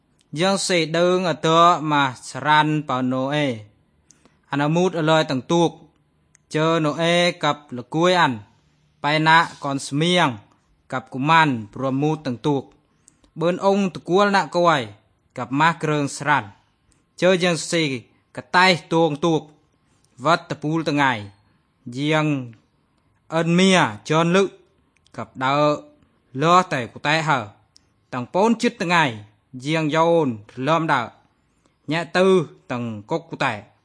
THe only thing that concerns e is that the tones are very muted.